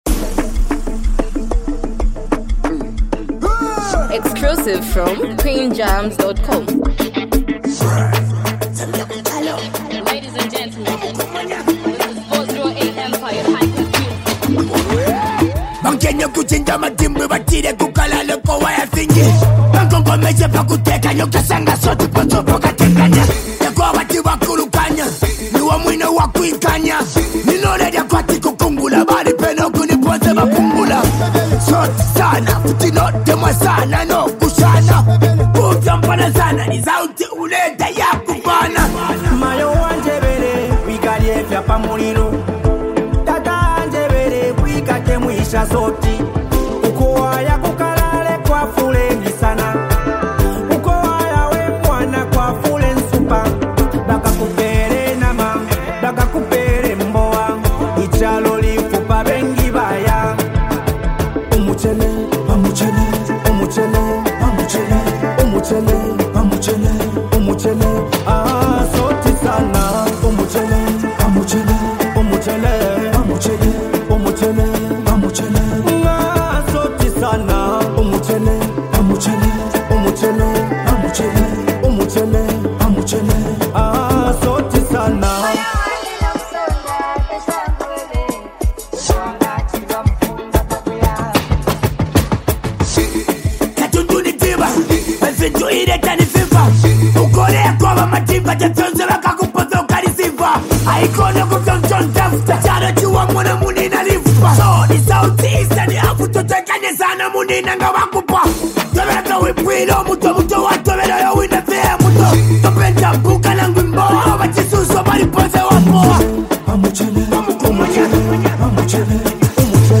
street-inspired, energetic song
His mature and assertive delivery
raw street energy